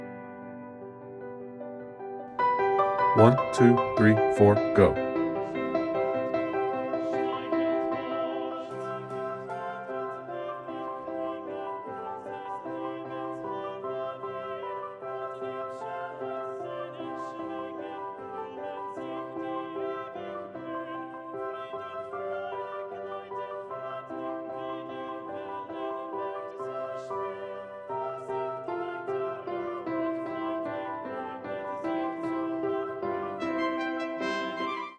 - Use one device to play the ACCOMPANIMENT ONLY (not vocal model) recording
Soprano/Alto Accompaniment Track